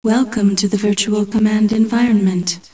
startup.wav